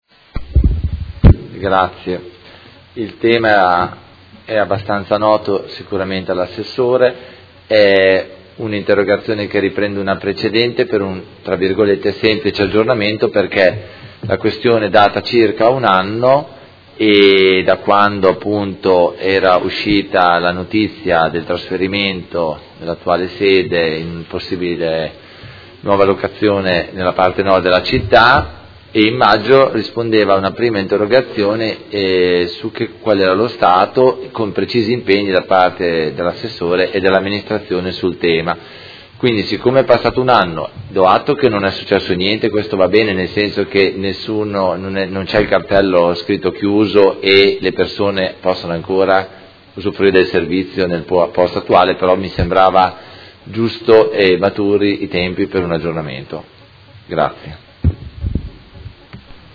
Seduta del 26/03/2018 Interrogazione del Consigliere Carpentieri (PD) avente per oggetto: Punto prelievi Modena est